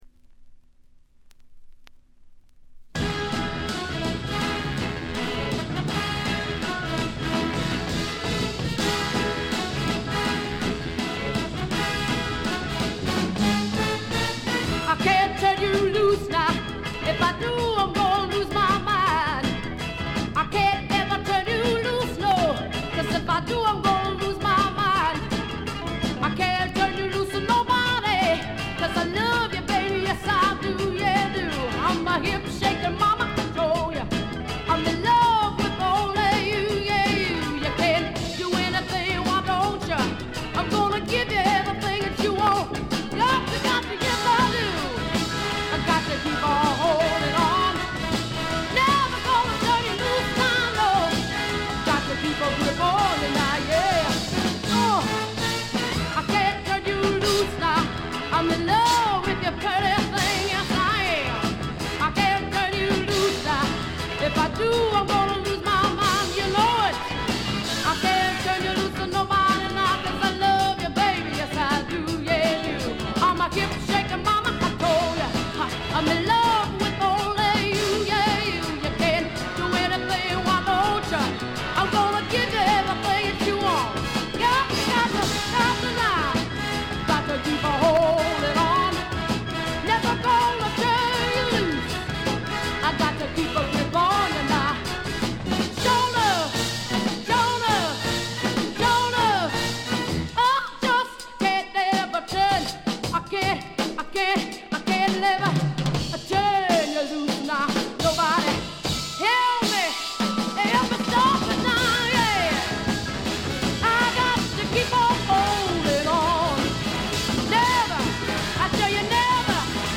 鑑賞を妨げるほどのノイズはありません。
試聴曲は現品からの取り込み音源です。